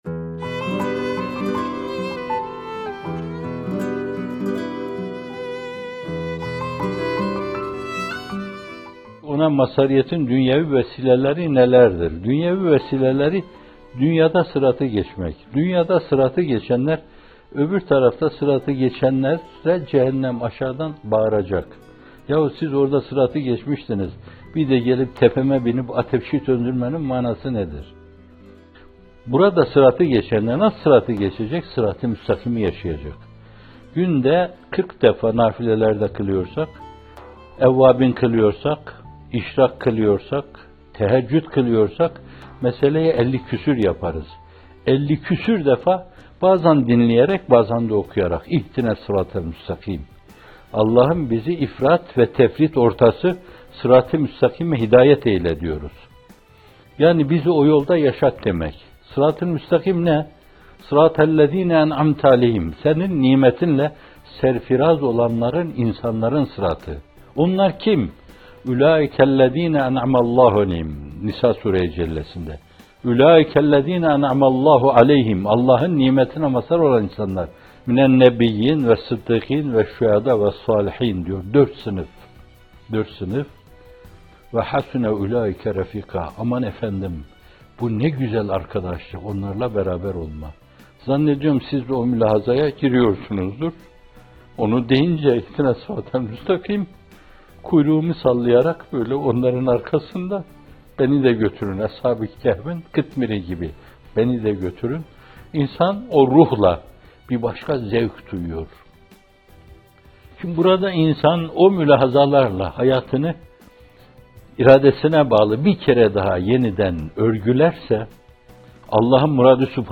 Bir Nefes (80) – Dünyada Sıratı Geçmek - Fethullah Gülen Hocaefendi'nin Sohbetleri